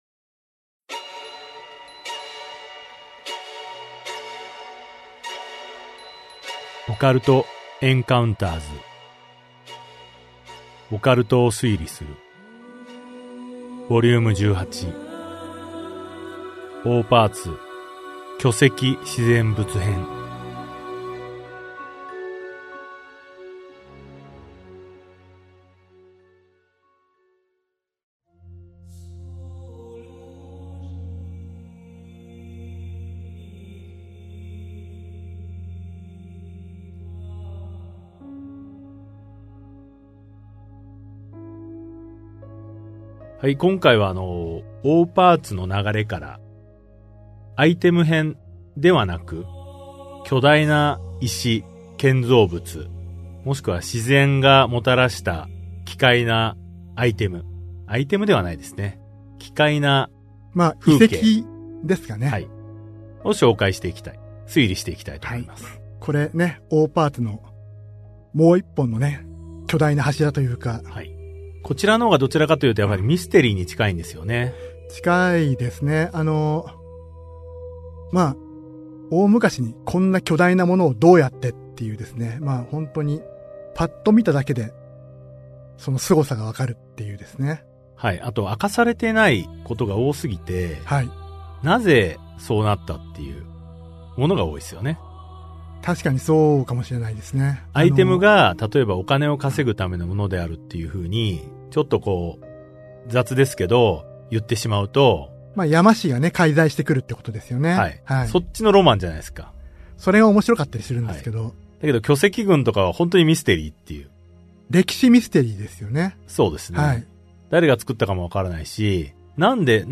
[オーディオブック] オカルト・エンカウンターズ オカルトを推理する Vol.18 オーパーツ 巨石編
オーパーツとは、その時代にはまだ無かった高度な技術や知識が使用された遺跡や遺物を指す。通称「場違いな工芸品」として知られるオーパーツの正体を、オカルトエンカウンターズの2人が現代的視点から推理する。